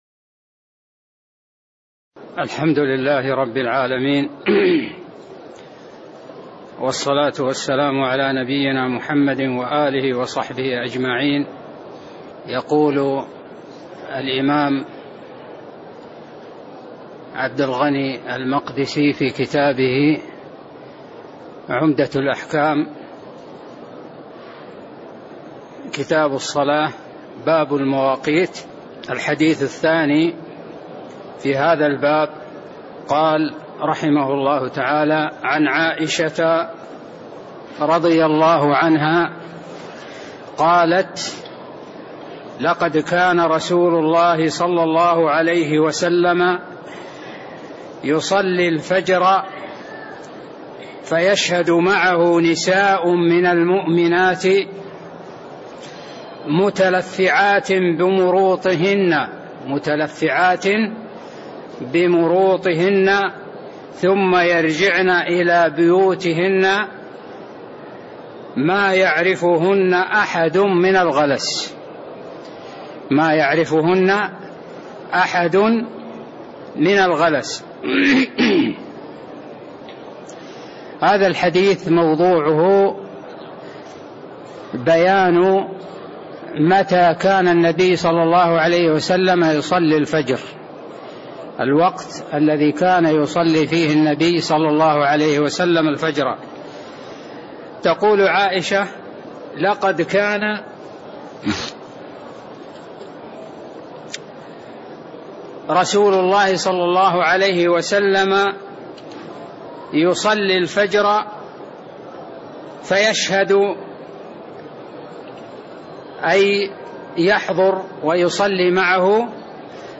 تاريخ النشر ٦ شعبان ١٤٣٥ هـ المكان: المسجد النبوي الشيخ